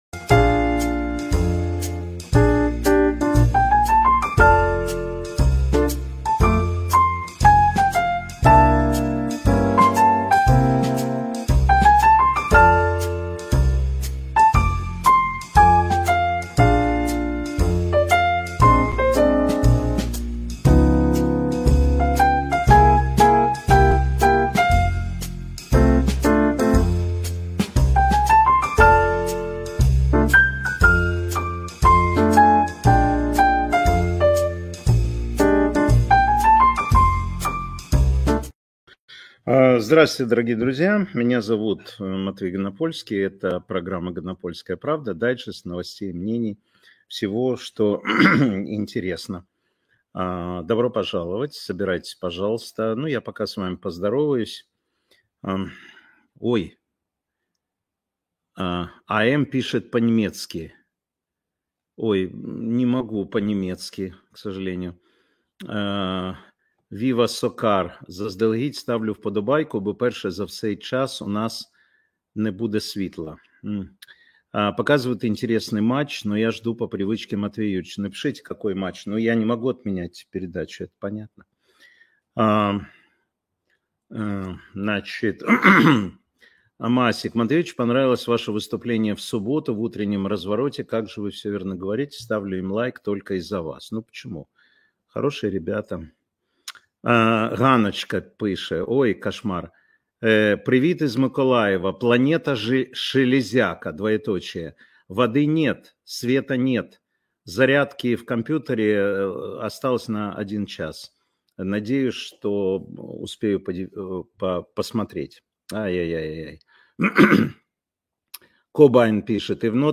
Эфир Матвея Ганапольского